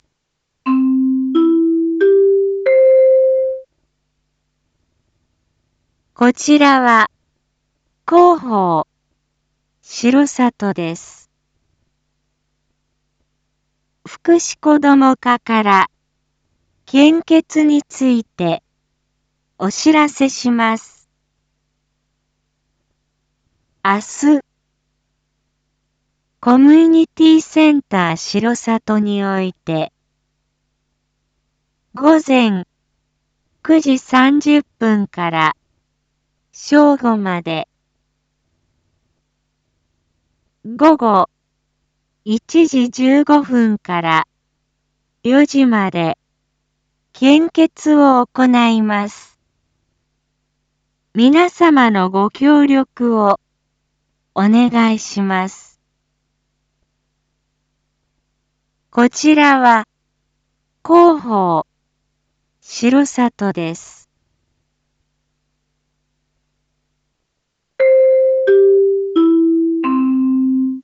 一般放送情報
Back Home 一般放送情報 音声放送 再生 一般放送情報 登録日時：2024-02-07 19:01:12 タイトル：「献血」ご協力のお願い インフォメーション：こちらは、広報しろさとです。 福祉こども課から献血について、お知らせします。